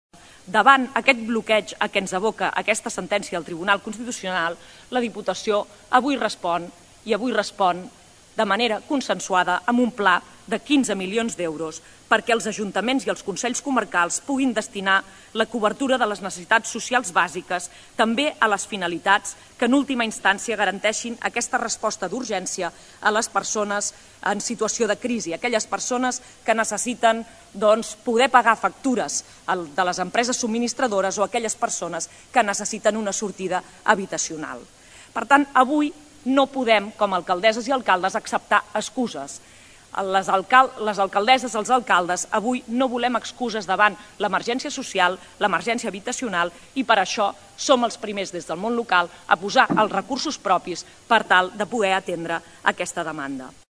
El nou programa, destinat als municipis i comarques de la demarcació, vol contribuir a que es pugui donar resposta a les situacions d’emergència energètica, a la cobertura de les necessitats socials bàsiques, el reforçament dels equips professionals de serveis socials, la cobertura de les situacions de necessitat social, la garantia d’un servei d’atenció domiciliària i de transport adaptat, així com el desplegament d’activitats socioeducatives, entre d’altres. Ho explica la presidenta de la Diputació, Mercè Conesa.